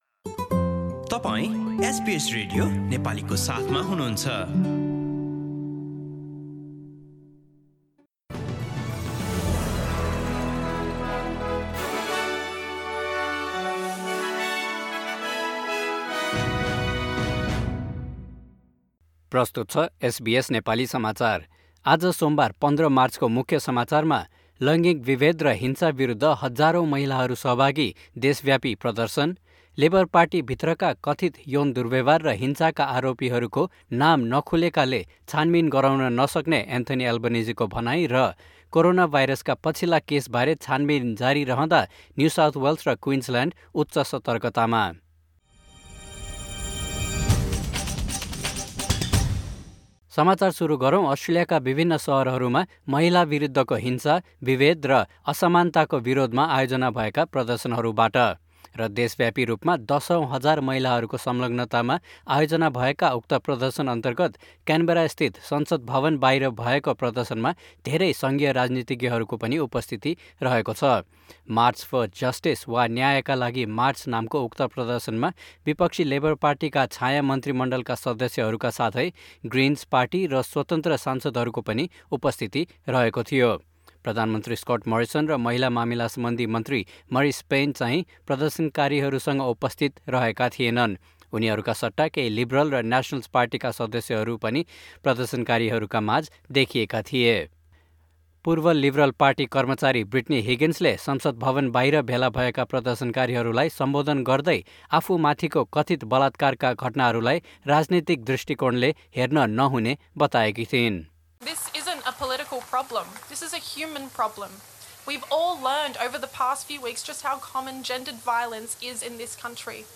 एसबीएस नेपाली अस्ट्रेलिया समाचार: सोमबार १५ मार्च २०२१